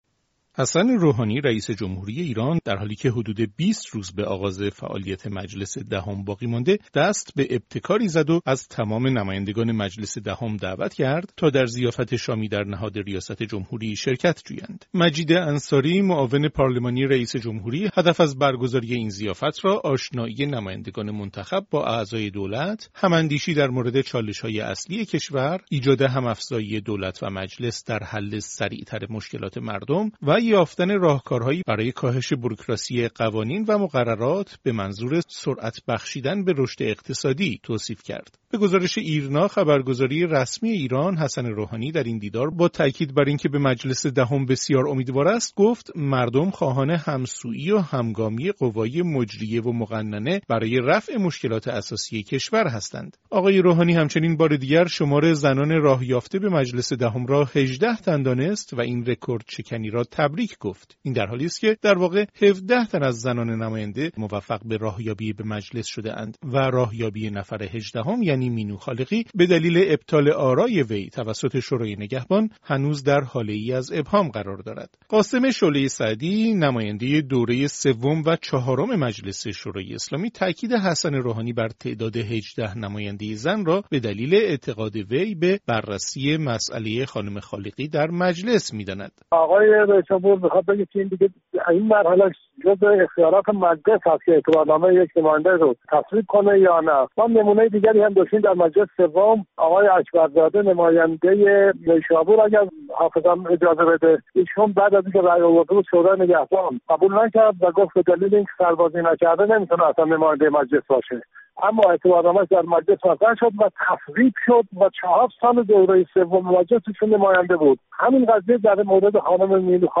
گزارش‌های رادیویی